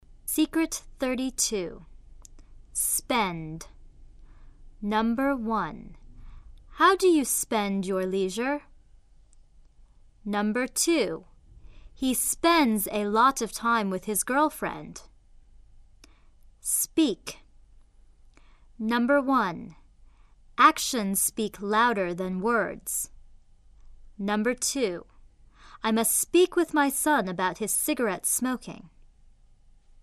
口语读音为[sp→sb +  元音]